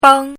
bēng
拼音： bēng
注音： ㄅㄥ